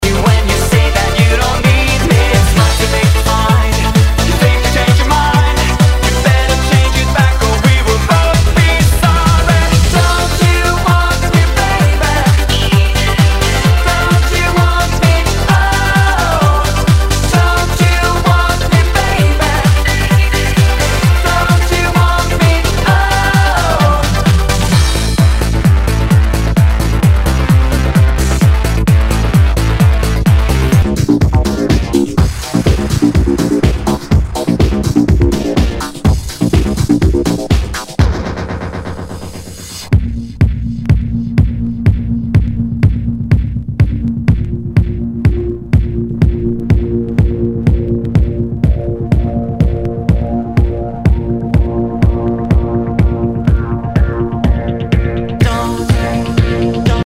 HOUSE/TECHNO/ELECTRO
ナイス！ユーロ・ヴォーカル・ハウス！